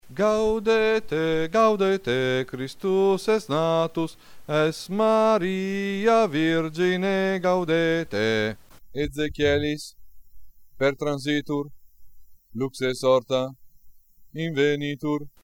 BASSI